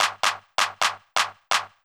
CLF Beat - Mix 8.wav